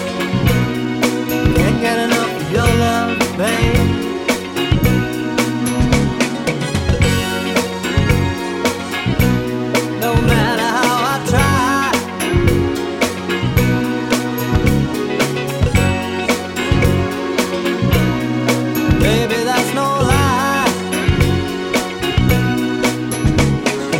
One Semitone Up Disco 3:49 Buy £1.50